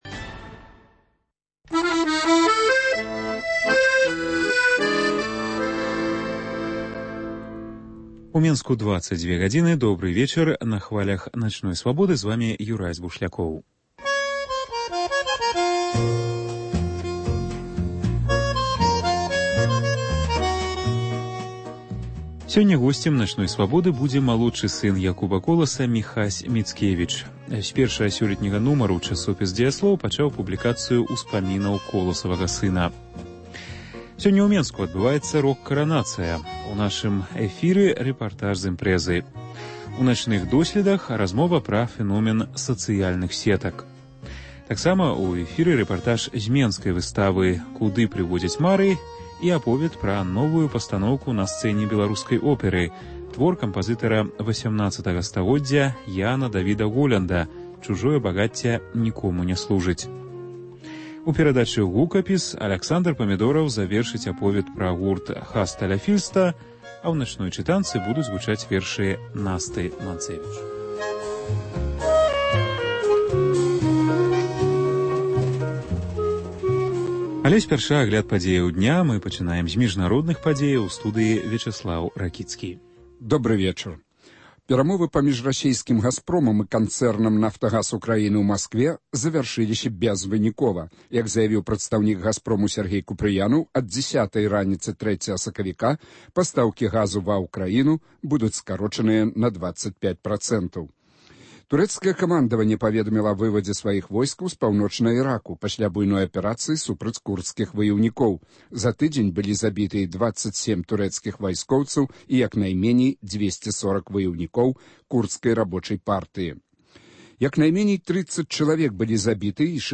* Сёньня ў Менску адбываецца “Рок-каранацыя” – жывы рэпартаж з імпрэзы. * “Начныя досьледы”: размова пра фэномэн сацыяльных сетак. * Апытаньне: чаго вам не хапае ў інтэрнэце?